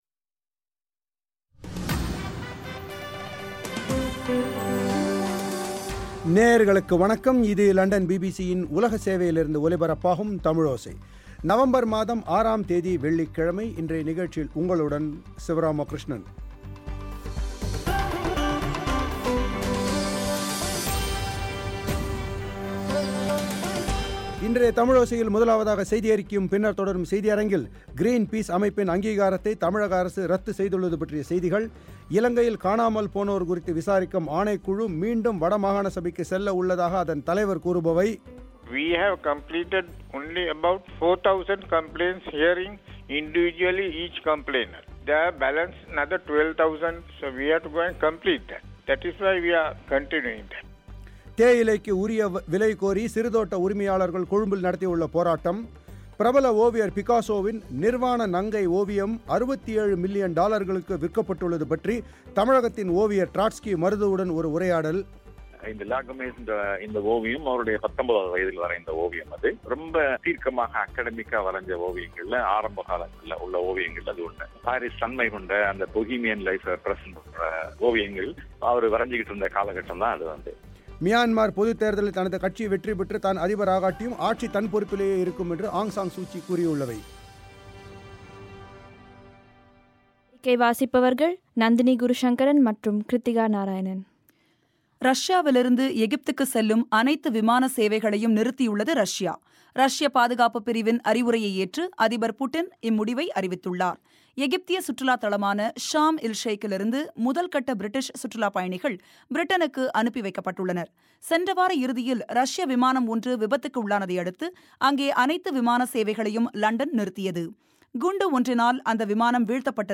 ஒரு உரையாடல்